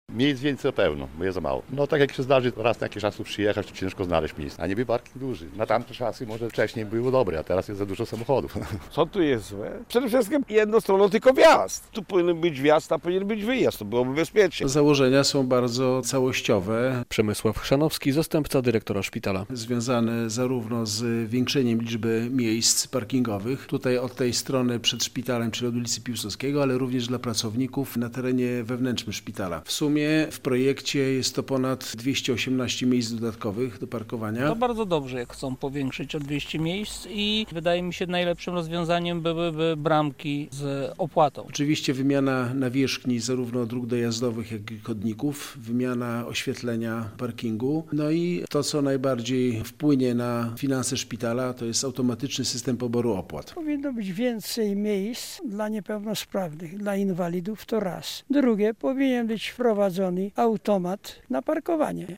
relacja
Często trzeba zrobić kilka okrążeń po parkingu, żeby znaleźć miejsce - mówi kierowca.